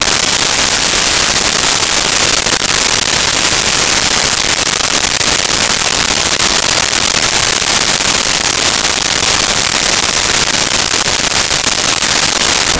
Fuse Sizzle
Fuse Sizzle.wav